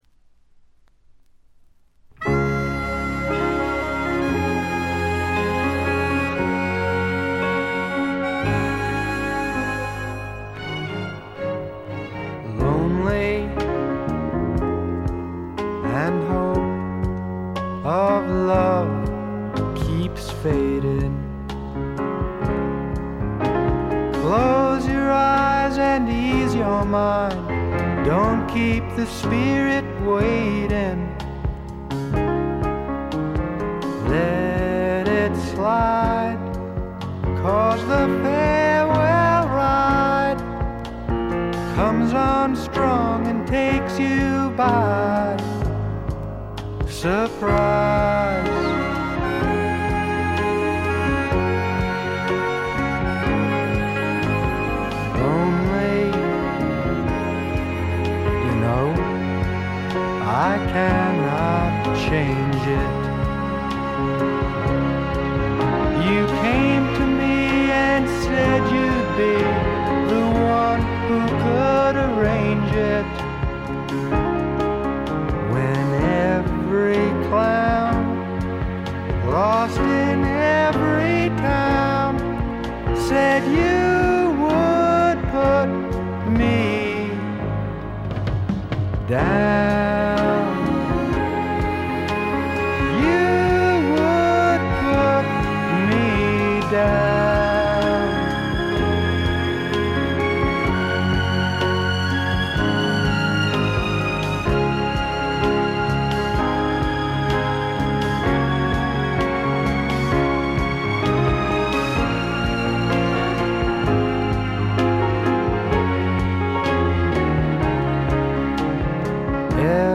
ほとんどノイズ感無し。
素晴らしい楽曲と素朴なヴォーカル。フォーキーな曲から軽いスワンプ風味を漂わせる曲までよく練られたアレンジもよいです。
試聴曲は現品からの取り込み音源です。
Vocals, Piano, Harmonica, Acoustic Guitar